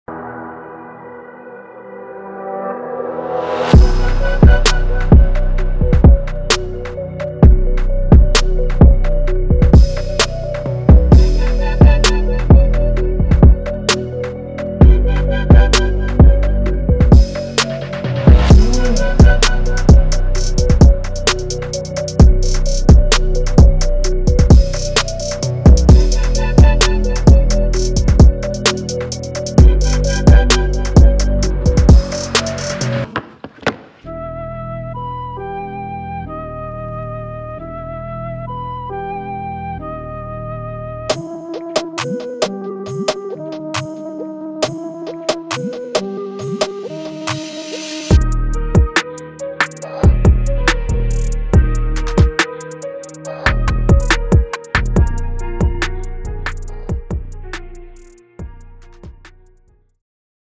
Drill
一款现代 Drill 和 Trap 样本包